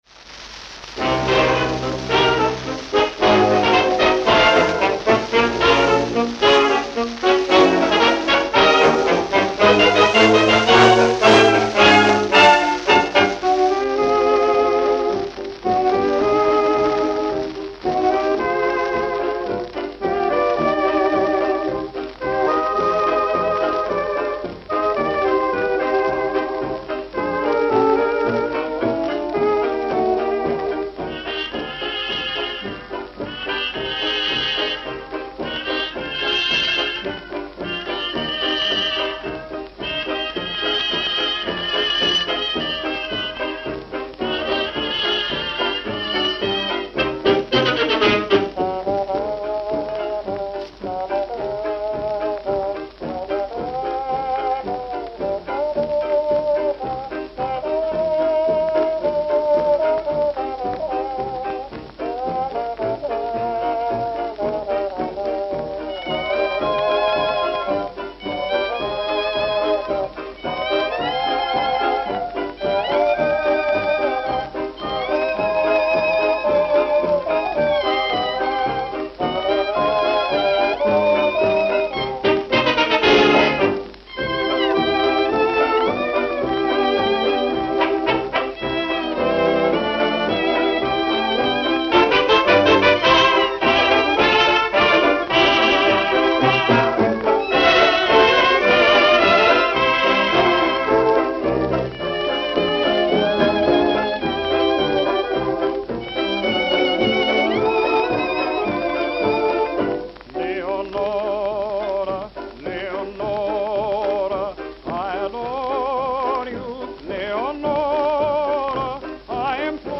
Note: Worn at beginning.